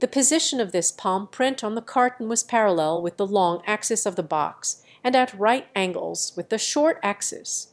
Matcha-TTS - [ICASSP 2024] 🍵 Matcha-TTS: A fast TTS architecture with conditional flow matching
VITS_5.wav